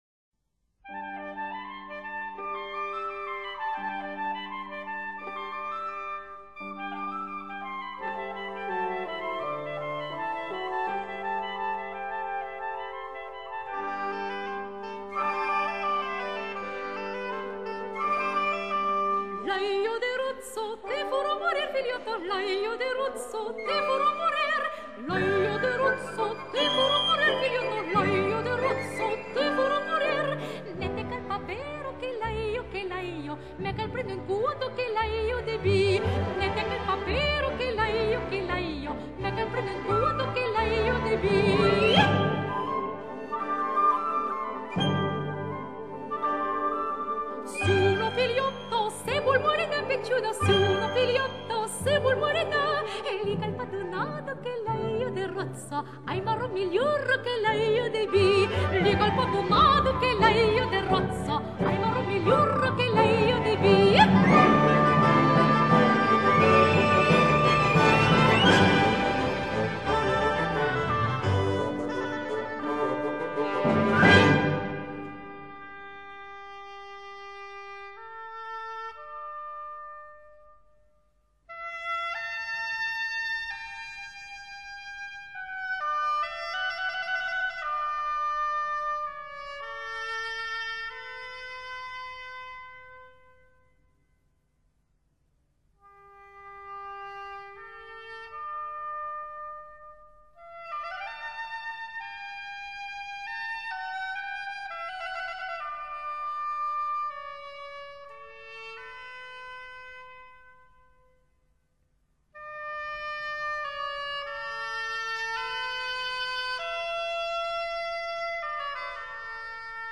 类型: Classic
一般由女高音演唱，乐队伴奏。
她的演唱再现了法国阿维农地区方言独特的清新韵致，以甜美而清澈的嗓音细腻地表现出来，使这些歌曲直入人心。
这全套唱片的录音也是不可多得，音色明亮而不尖锐，音质丰润、音场宽松，更重要是它的活生生的感觉。